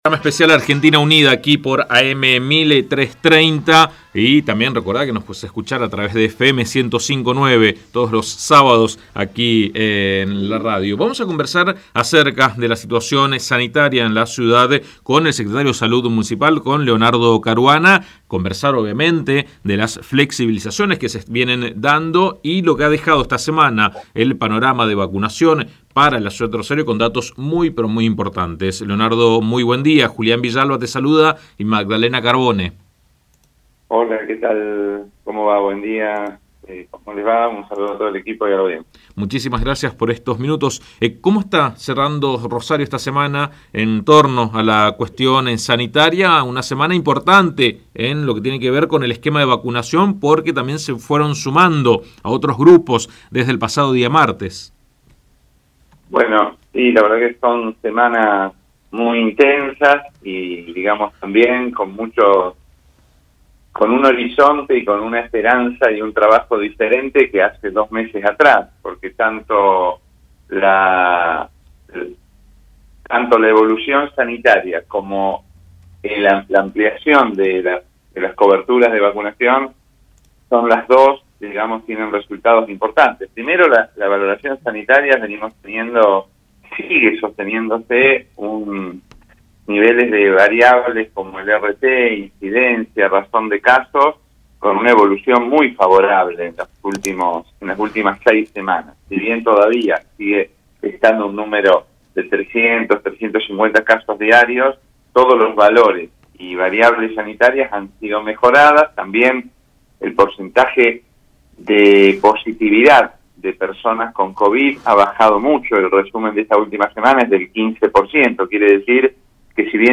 El secretario de salud municipal, Leonardo Caruana, habló con AM 1330 y explicó cómo se viene desarrollando la vacunación a los distintos grupos etarios.